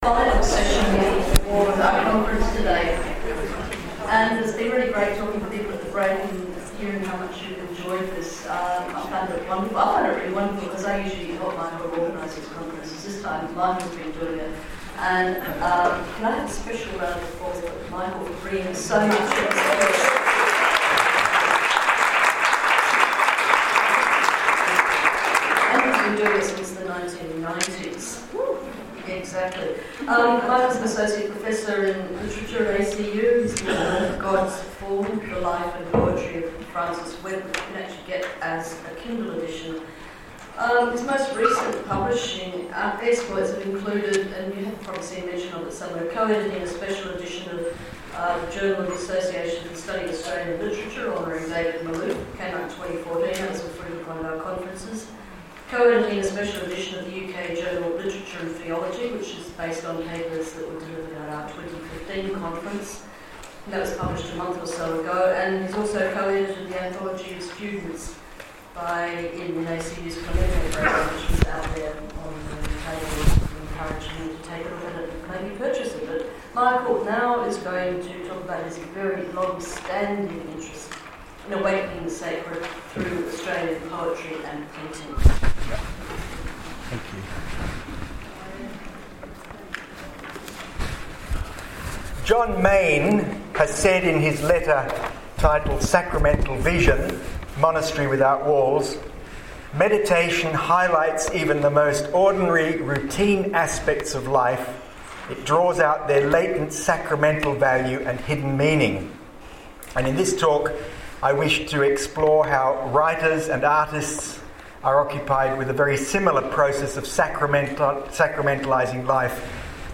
Awakening the Sacred in Australian Literature and Art- Keynote Address 8th July 2017
awakening-the-sacred-lecture-mg.mp3